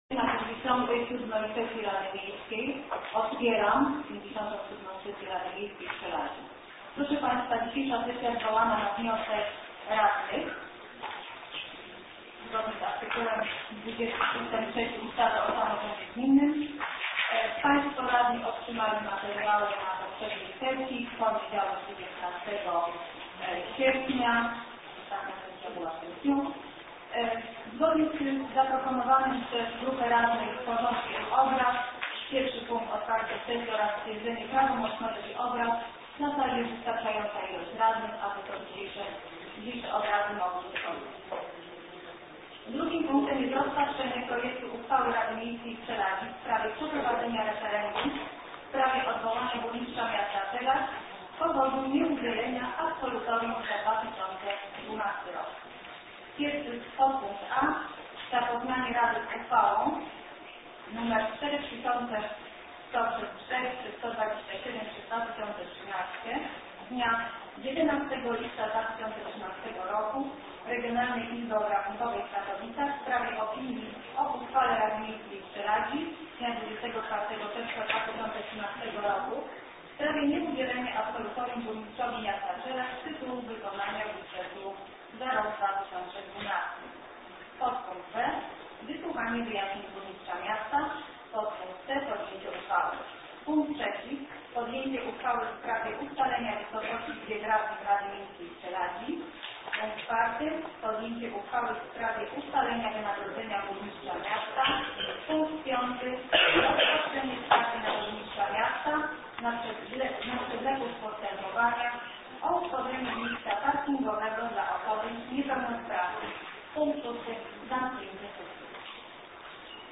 Plik dźwiękowy z Sesji Rady Miejskiej nr LVII z dnia 22.08.2013r.